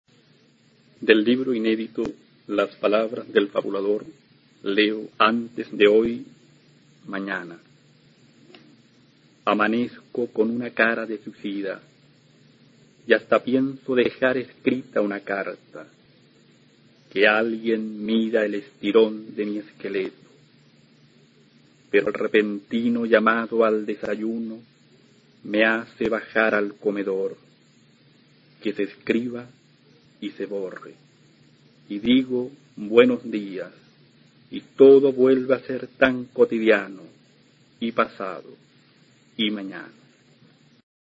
A continuación se puede escuchar a Jaime Quezada, autor chileno de la Generación del 60, recitando su poema Antes de hoy, mañana, del libro "Las palabras del fabulador" (1968).
Poema